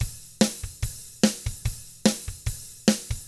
146ROCK T4-R.wav